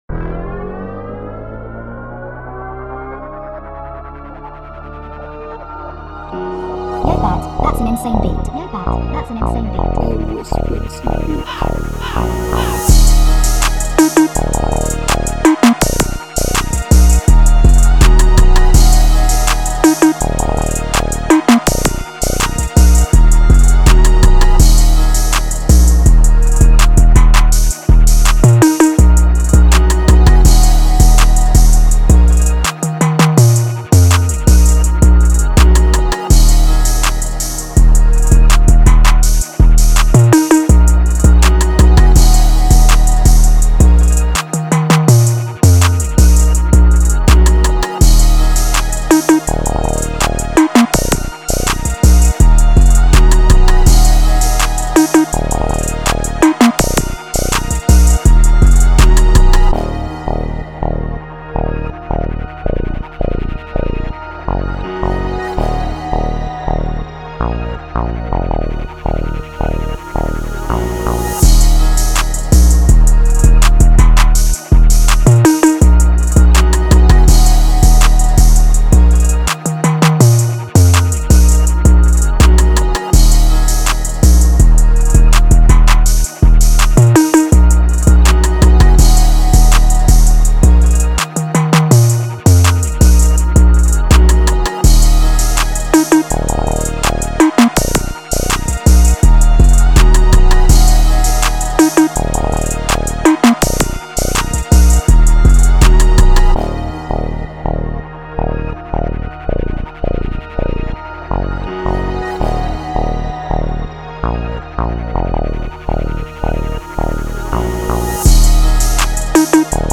Hard Detroit
BPM 164 Key A#/Bb Genre Trap
Bit chill